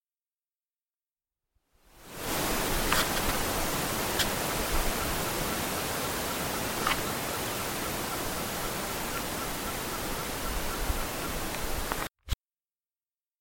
Caburé Grande (Glaucidium nana)
Nombre en inglés: Austral Pygmy Owl
Localidad o área protegida: Villa Pehuenia
Condición: Silvestre
Certeza: Vocalización Grabada
Cabure-grande.mp3